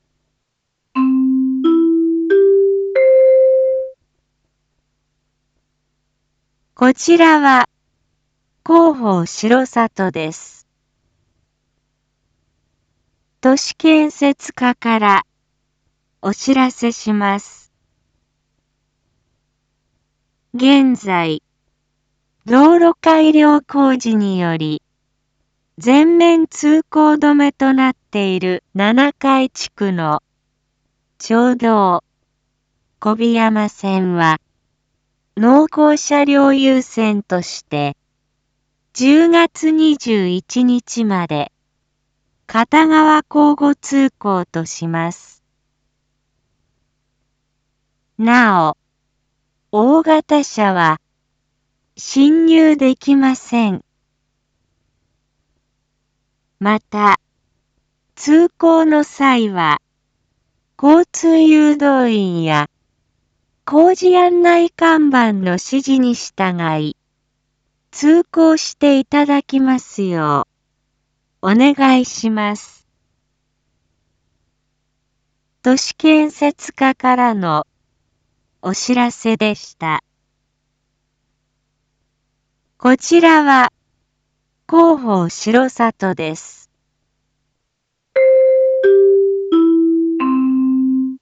Back Home 一般放送情報 音声放送 再生 一般放送情報 登録日時：2022-09-16 19:01:32 タイトル：町道４号線 交通規制について（七会地区限定） インフォメーション：こちらは広報しろさとです。